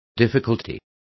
Complete with pronunciation of the translation of difficulty.